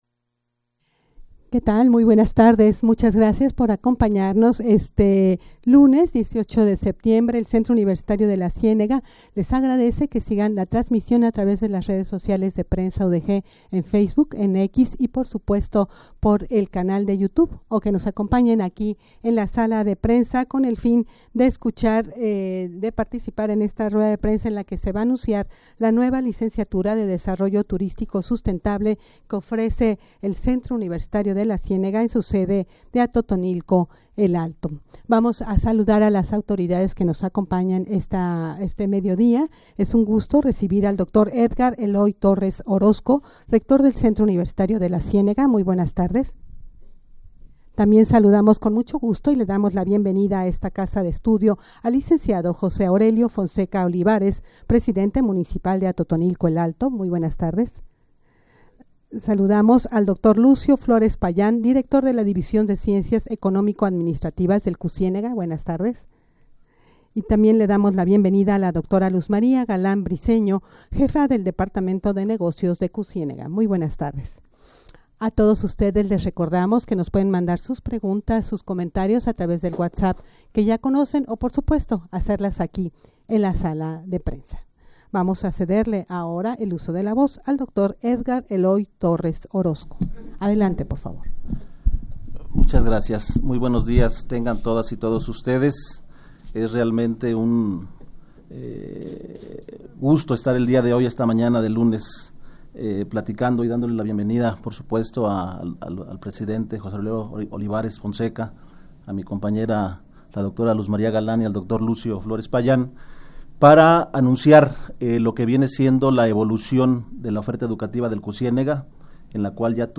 Audio de la Rueda de Prensa
rueda-de-prensa-para-anunciar-la-nueva-licenciatura-de-desarrollo-turistico-sustentable-que-ofrece-el-cucienega.mp3